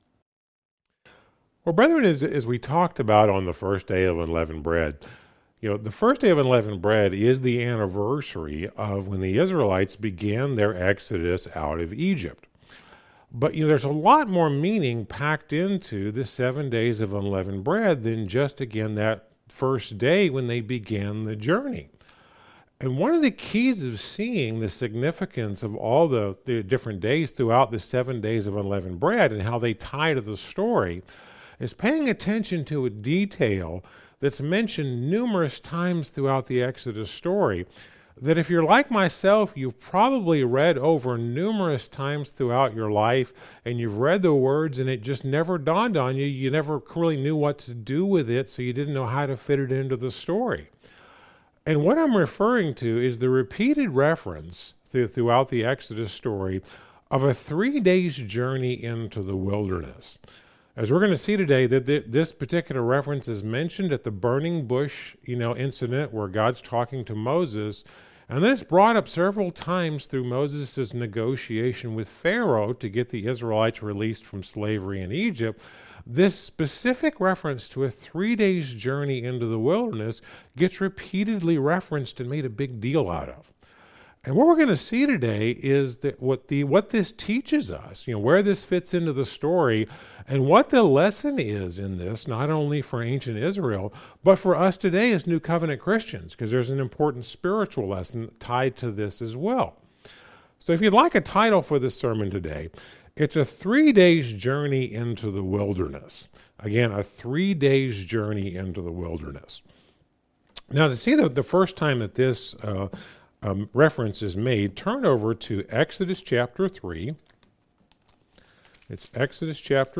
Sermons – Searching The Scriptures